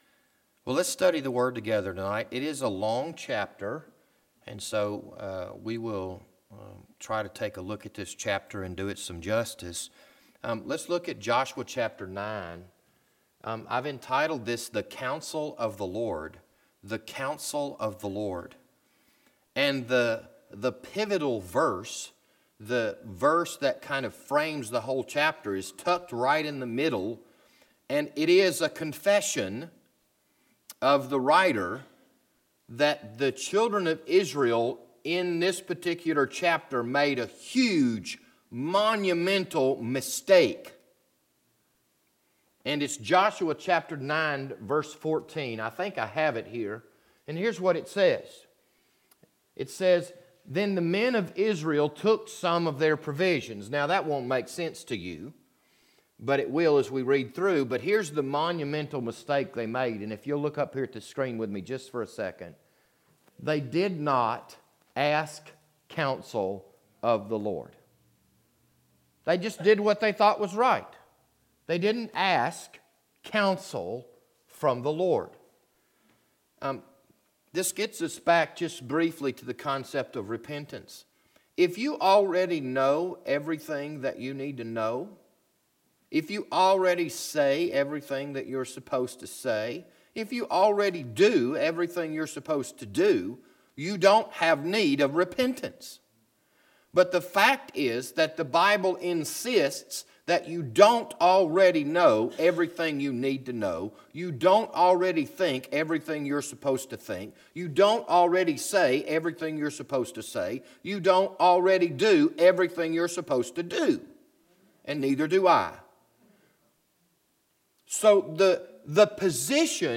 This Sunday evening sermon was recorded on September 22nd, 2019.